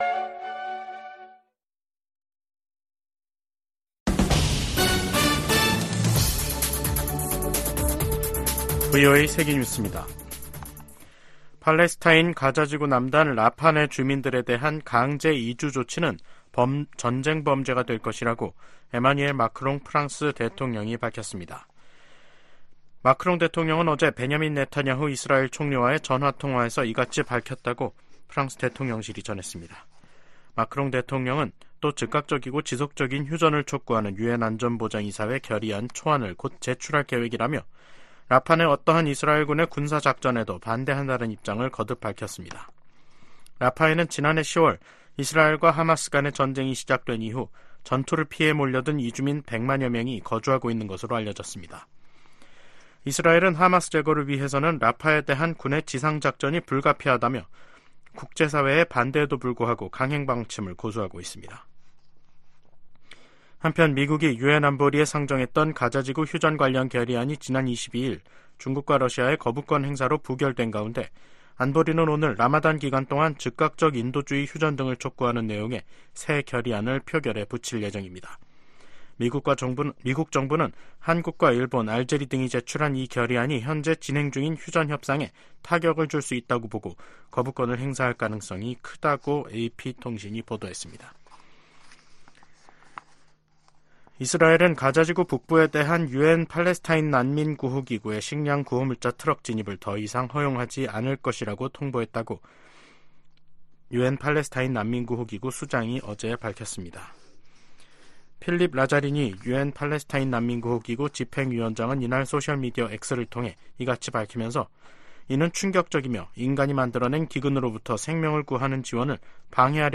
VOA 한국어 간판 뉴스 프로그램 '뉴스 투데이', 2024년 3월 25일 2부 방송입니다. 러시아가 우크라이나를 향해 최소 10차례에 걸쳐 북한제 탄도미사일 40여 발을 발사했다고 로버트 우드 유엔주재 미국 차석대사가 밝혔습니다. 김여정 북한 노동당 부부장은 기시다 후미오 일본 총리로부터 정상회담 제의를 받았다고 밝혔습니다. 미 국무부는 북한에 고문 증거 없다는 중국 주장을 일축하고, 고문방지협약에 따른 송환 금지 의무 준수를 촉구했습니다.